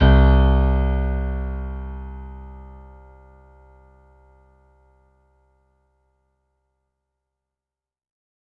55ay-pno06-c1.wav